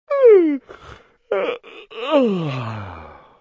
из Червячков... зевота.. на sms не плохо
More_snore.mp3.32.mp3